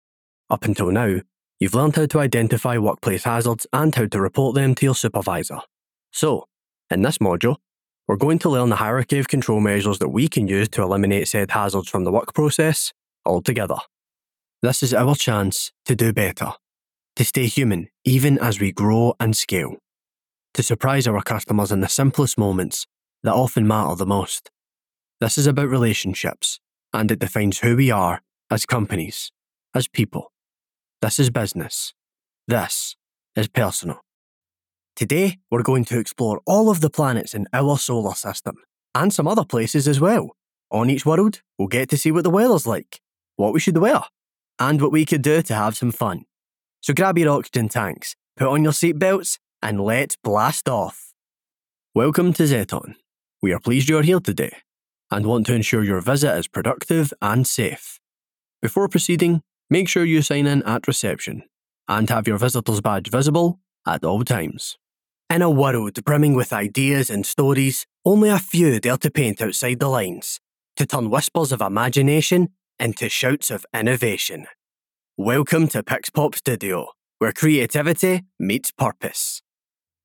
Male
Yng Adult (18-29)
My accent is a Scottish central belt, Glaswegian accent, though clear and informative
To conversational, and inviting , friendly tone
E-Learning & Corporate Reads
All our voice actors have professional broadcast quality recording studios.